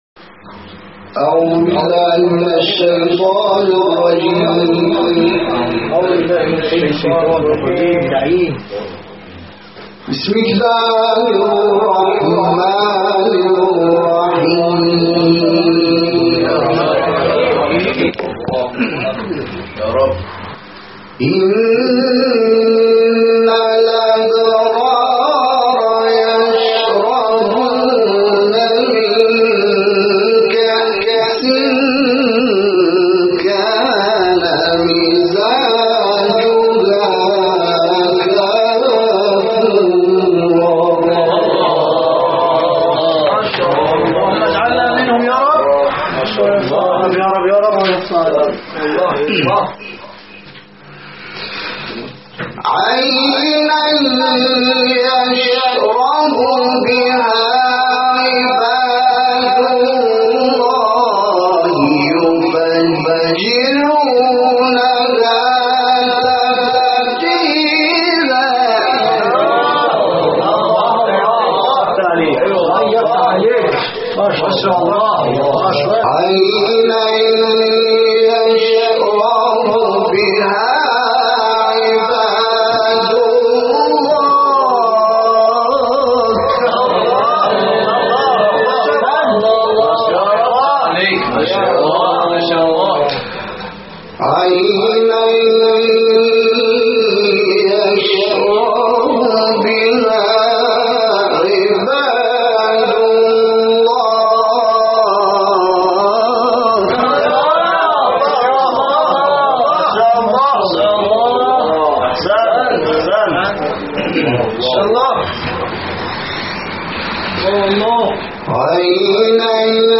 این تلاوت به سبک استاد حصان اجرا شده و مدت زمان آن یازده دقیقه است.